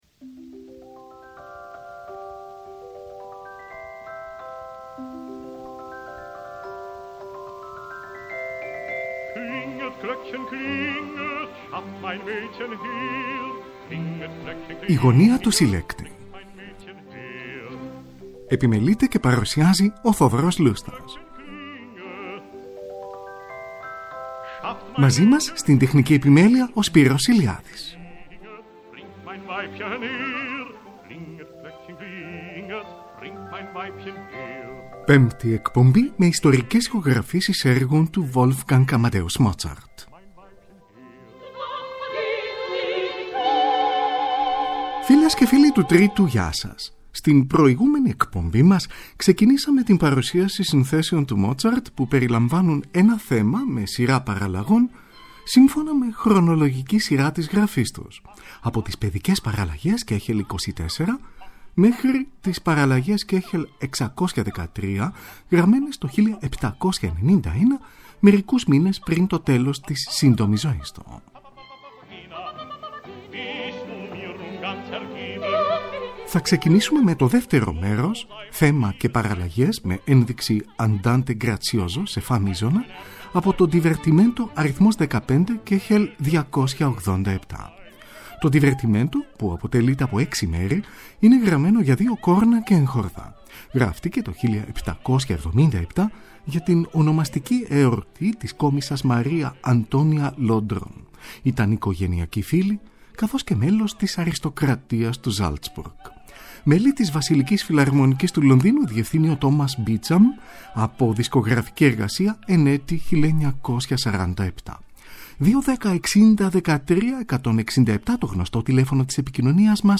ΙΣΤΟΡΙΚΕΣ ΗΧΟΓΡΑΦΗΣΕΙΣ ΕΡΓΩΝ ΤΟΥ WOLFGANG AMADEUS MOZART (ΠΕΜΠΤΟ ΜΕΡΟΣ)
Σονάτα για πληκτροφόρο, Κ. 331: πρώτο μέρος (θέμα και έξι παραλλαγές).